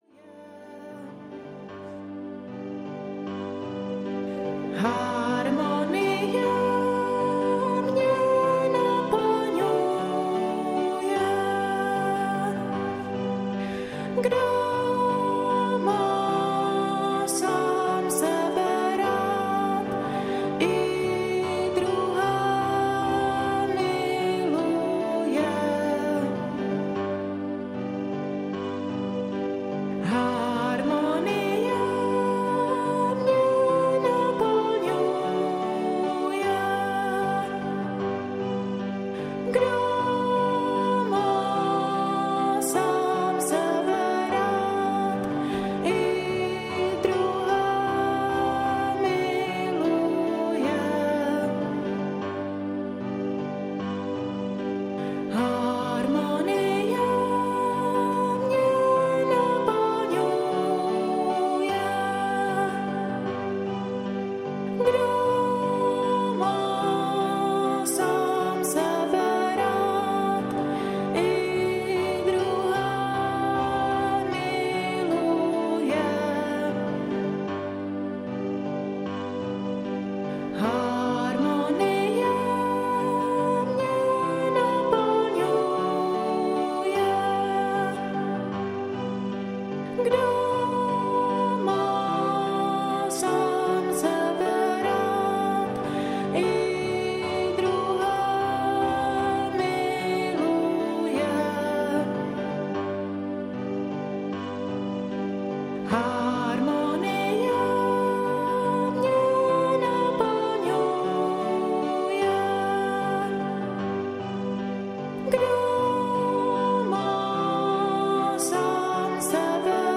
Harmonie audiokniha
Ukázka z knihy
Já jsem já.“3. Relaxační hudba „Léčivá flétna „ - tóny flétny harmonizují energii v těle